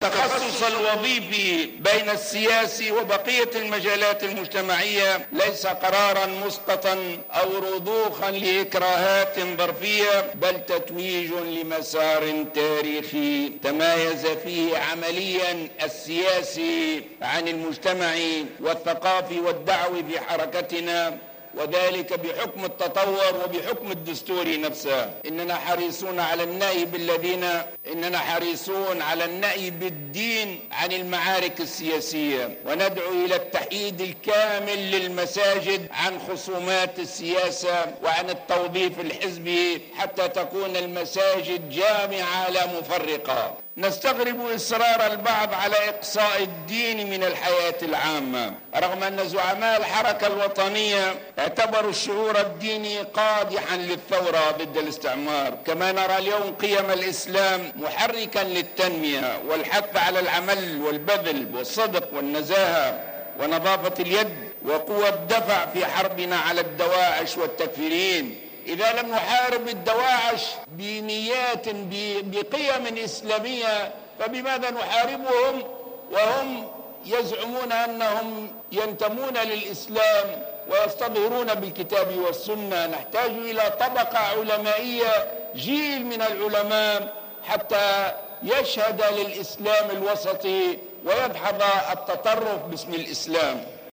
وأضاف الغنوشي، في افتتاح المؤتمر العاشر لحركة النهضة بالقاعة الرياضية المغطاة برادس، مساء اليوم الجمعة، أن النهضة تطورت خلال هذا المسار من حركة عقدية إلى حزب وطني ديمقراطي مسلم مرورا بمرحلة كانت فيها حركة احتجاجية تدعو إلى الديمقراطية، موضحا أن الحركة جادة في الإستفادة من أخطائها.